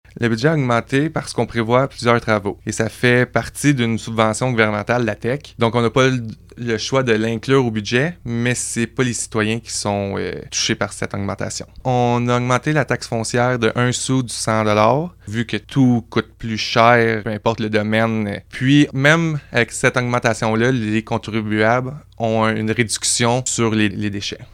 Le maire de Bois-Franc, Samuel Malette, explique que ces décisions ont été prises en raison de l’inflation et d’investissements routiers prévus dans les prochaines années :